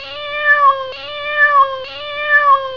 Miau, Miau, Miau !!ELS GATS